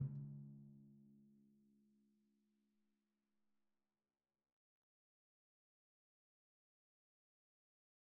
Timpani3_Hit_v1_rr2_Sum.wav